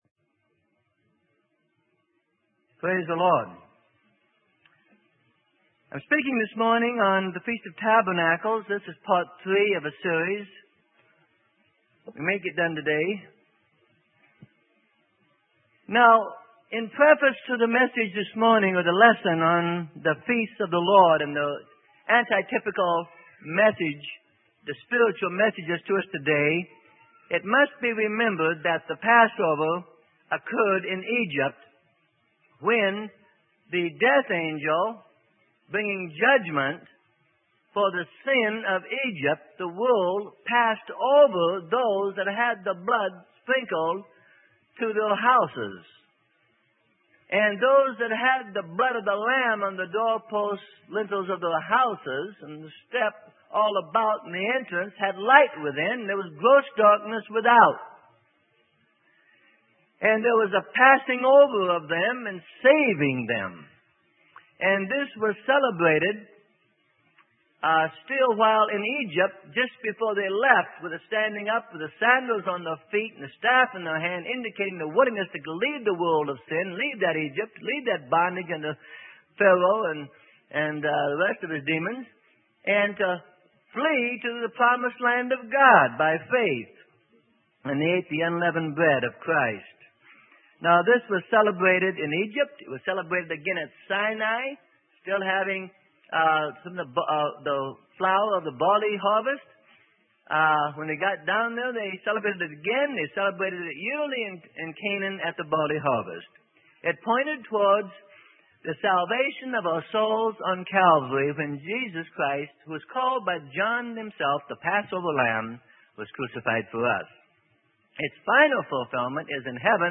Sermon: Feast of Tabernacles - Part 3 - Freely Given Online Library